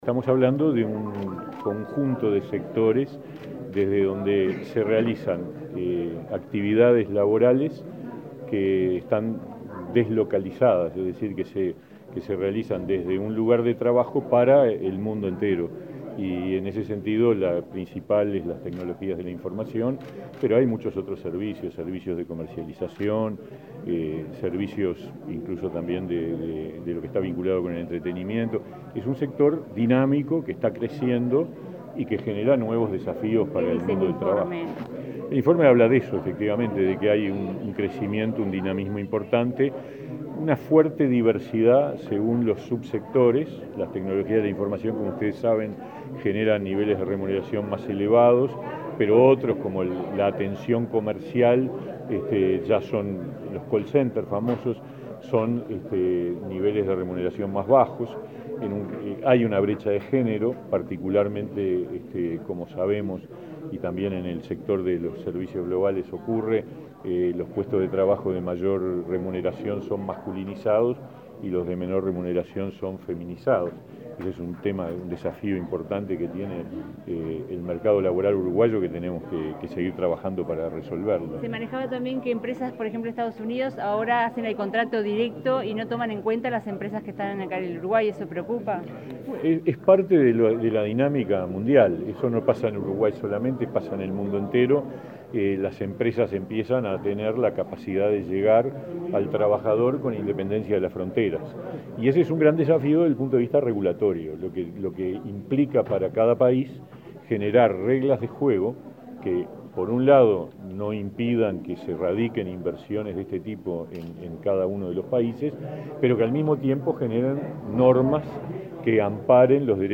Declaraciones del ministro de Trabajo, Pablo Mieres
El Ministerio de Trabajo y Seguridad Social, la Organización de las Naciones Unidas en Uruguay y la Oficina de la Organización Internacional del Trabajo (OIT) para el Cono Sur de América Latina presentaron este viernes 11 en Montevideo el informe “Evolución y desafíos del sector servicios globales en Uruguay: condiciones laborales, brechas de género y orientaciones de política”. Luego el ministro Pablo Mieres dialogó con la prensa.